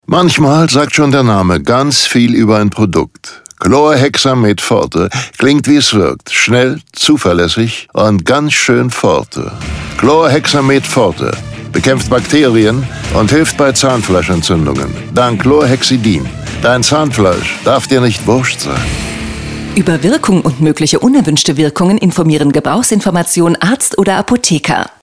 Unter folgendem Link finden sie unseren Chlorhexamed-Radiospot: